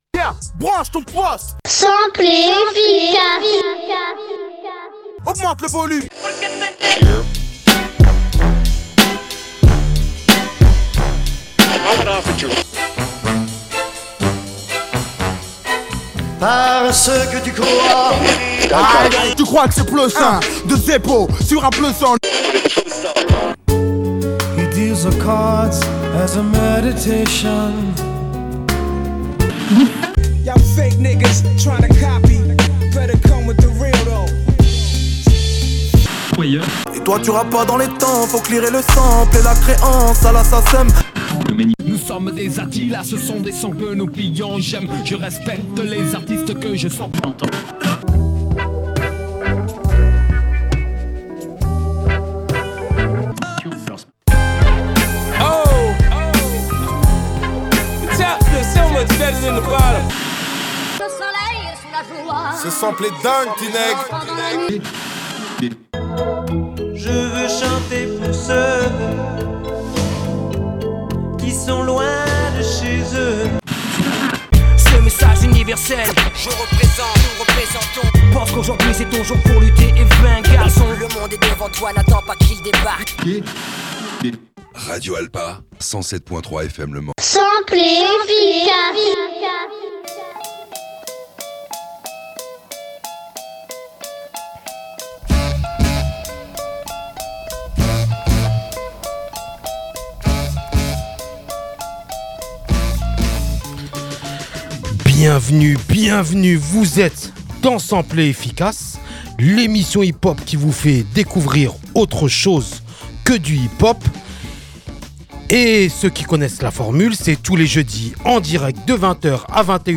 Aujourd’hui, on va traverser plusieurs territoires : le Ghana et ses cuivres solaires entre highlife et afrobeat, le Nigeria et ses expérimentations funk et synthétiques, le Cameroun et l’afro-rock psyché